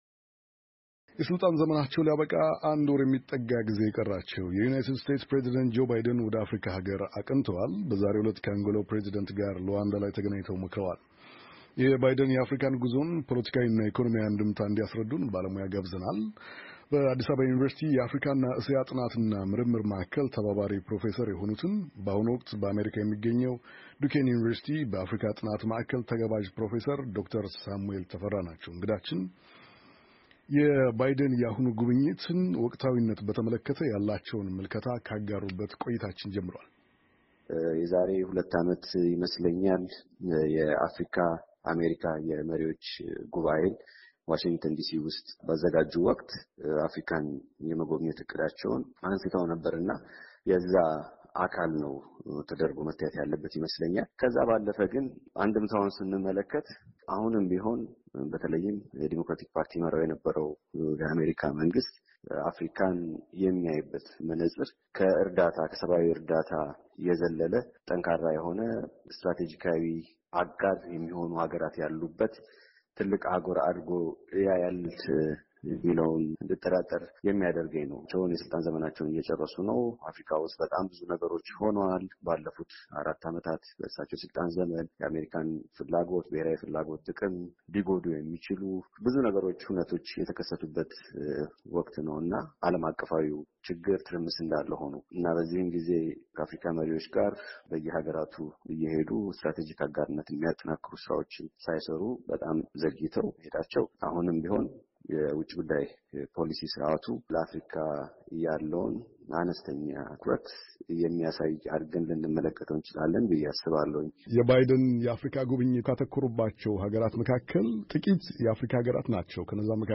የባይደን የአፍሪካ ጉዞን ፖለቲካዊ እና ኢኮኖሚያዊ አንድምታ እንዲያስረዱን ባለሞያ ጋብዘናል።